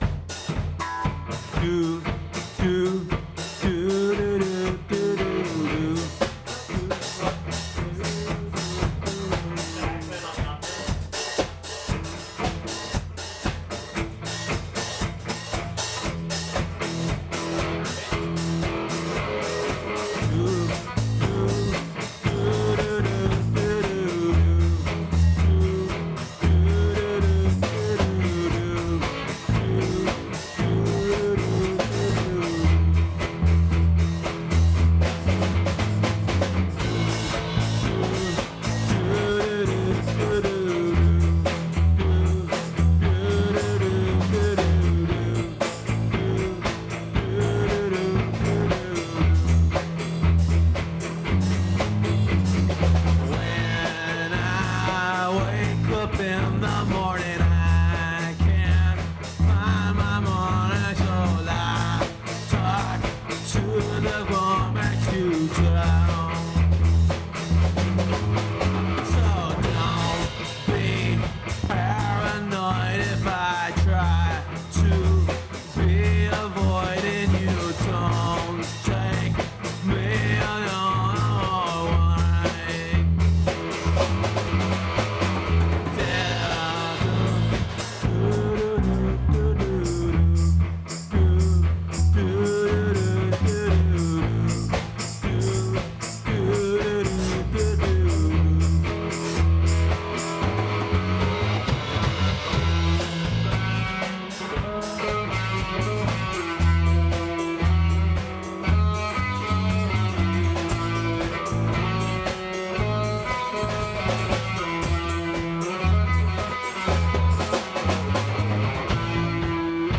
- tempo con moderate rock jam session
- off key
home studio
distorted electric rhythm guitar
bass
lead guitar
The band concludes with laughter.